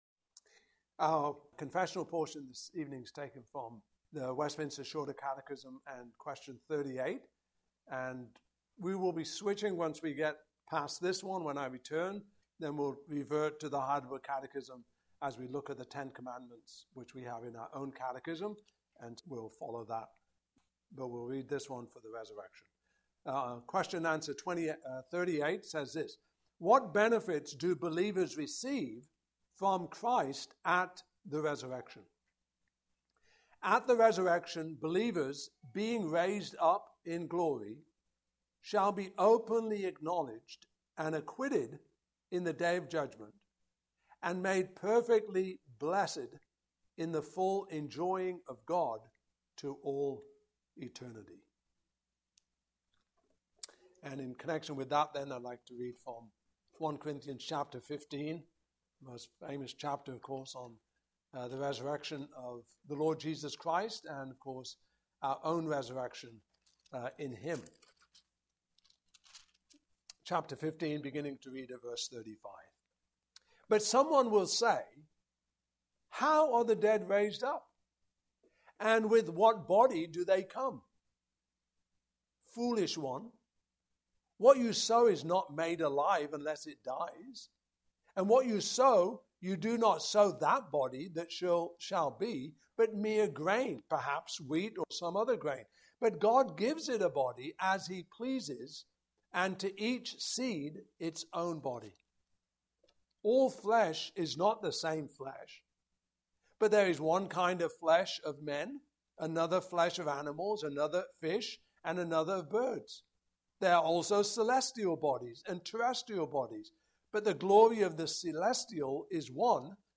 Passage: I Corinthians 15:35-58 Service Type: Evening Service